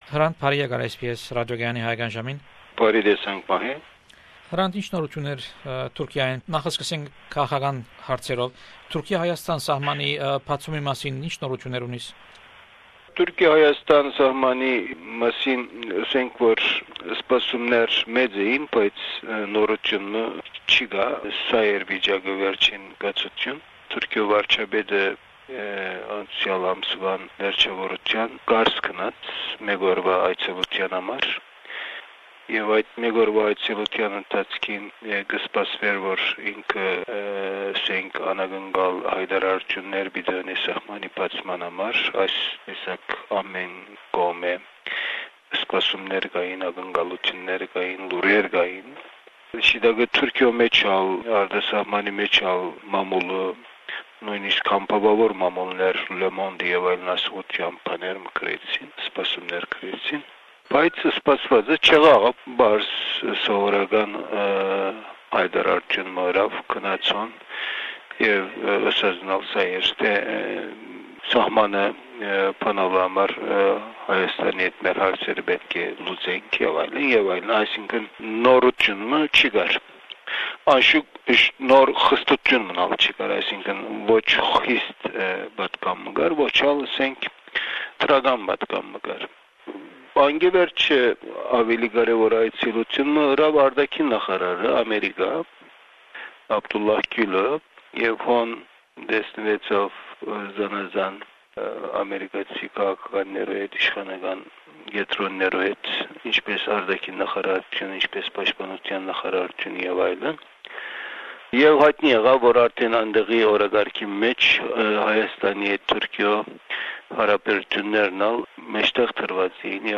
This is the fourth interview with Hrant dink, 4 July 2003.